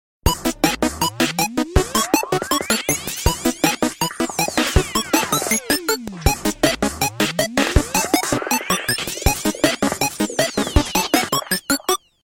nokia-thistle_24538.mp3